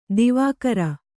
♪ divākara